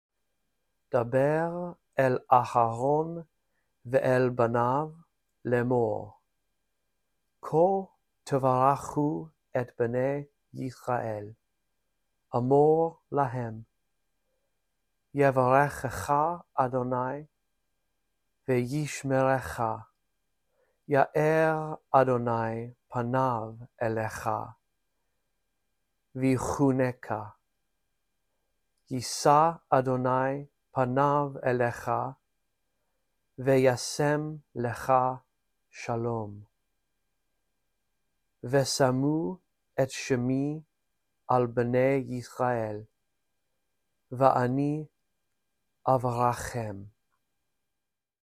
1. Listen to me read all of Numbers 6:23-27, following along in the text below.